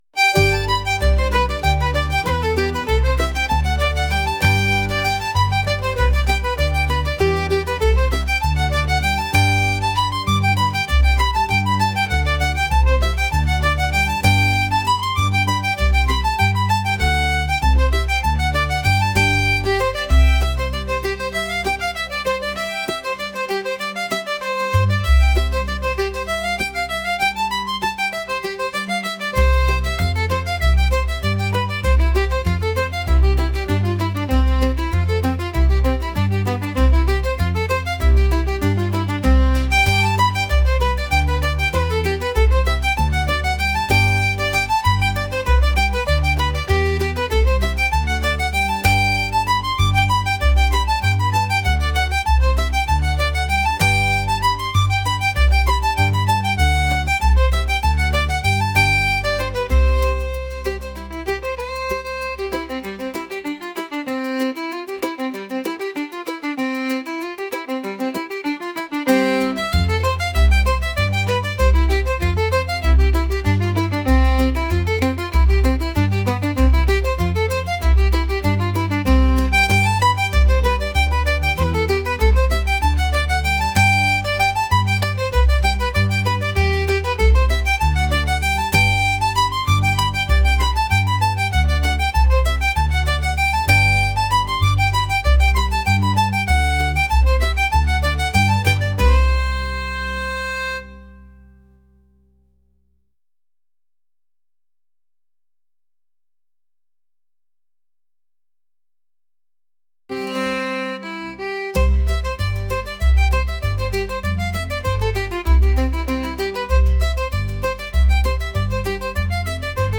traditional | lively | folk